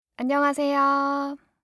알림음 8_안녕하세요2-여자.mp3